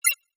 Holographic UI Sounds 6.wav